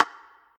spinwheel_tick_07.ogg